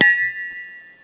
morph.wav